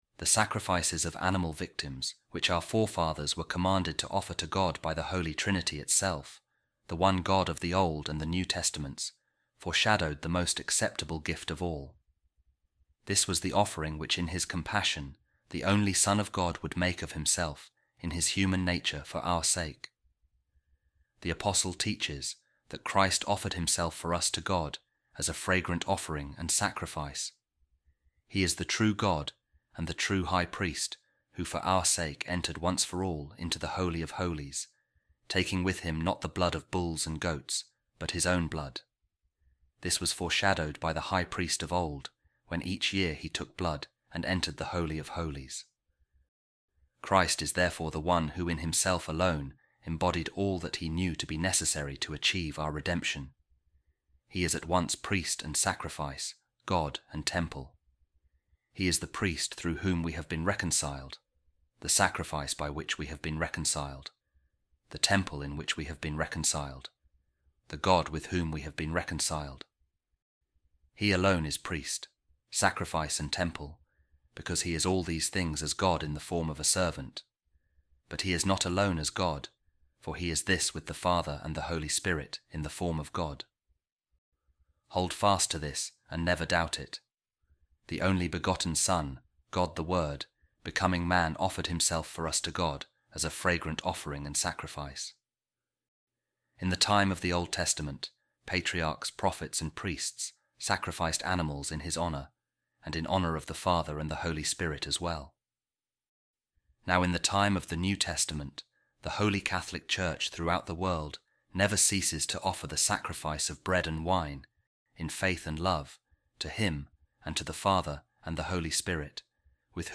A Reading From The Treatise Of Saint Fulgentius Of Ruspe To Peter On Faith